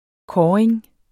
Udtale [ ˈkɒːɐ̯eŋ ]